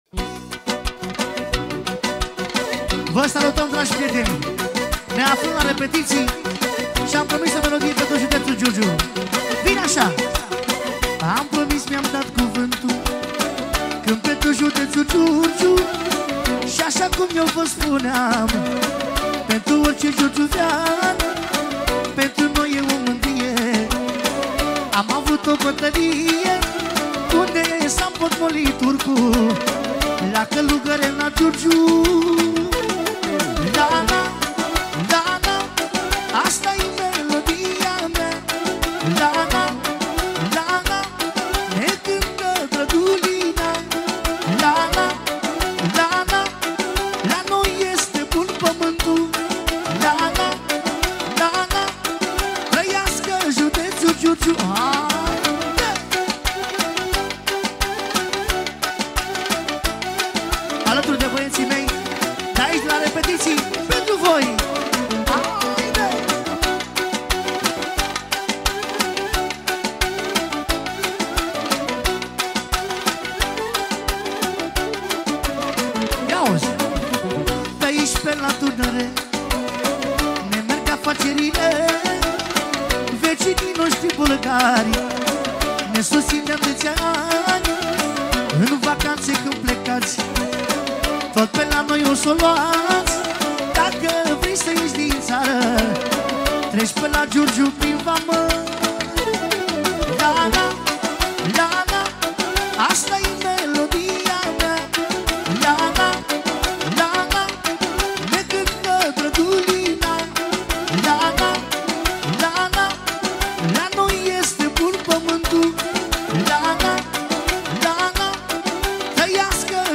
Live
Data: 26.10.2024  Manele New-Live Hits: 0